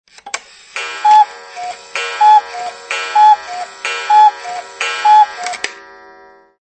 Reloj de cuco
El sonido que emiten los relojes de cuco en cada hora en punto.
tonosmovil.net_reloj_de_cuco.mp3